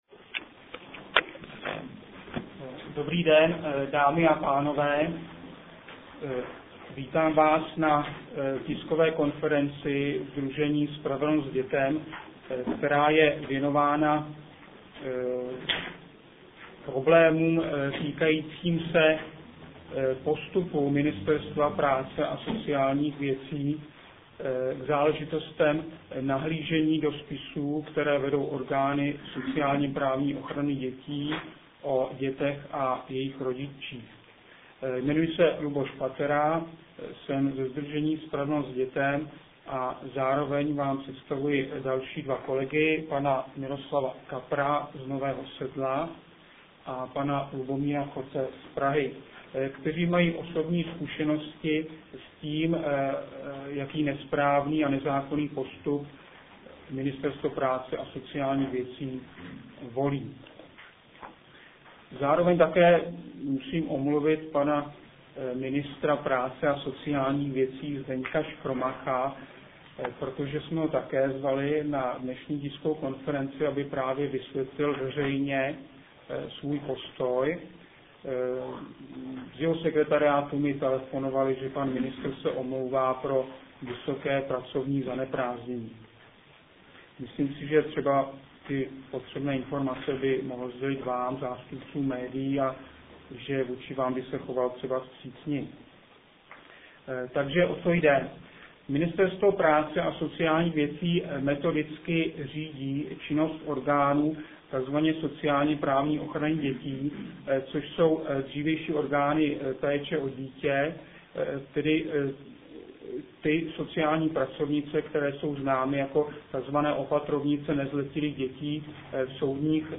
Zvukov� z�znam z tiskov� konference 4.5.2004